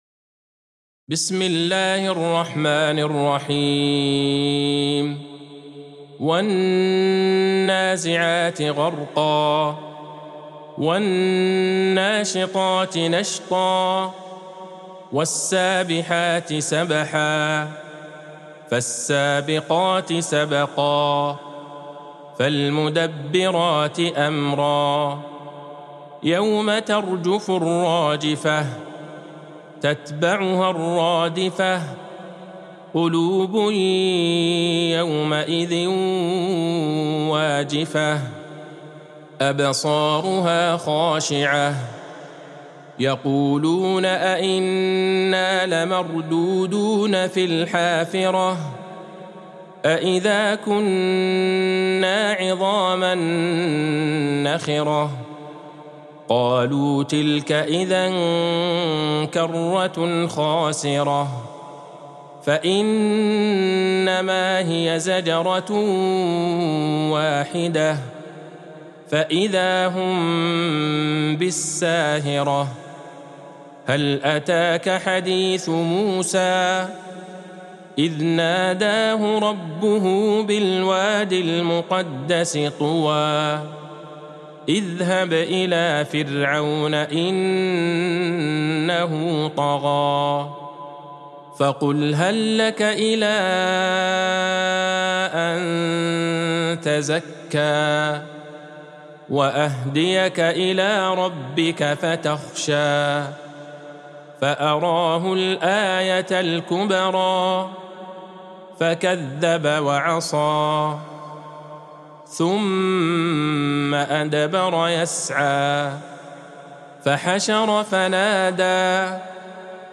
سورة النازعات Surat An-Nazat | مصحف المقارئ القرآنية > الختمة المرتلة ( مصحف المقارئ القرآنية) للشيخ عبدالله البعيجان > المصحف - تلاوات الحرمين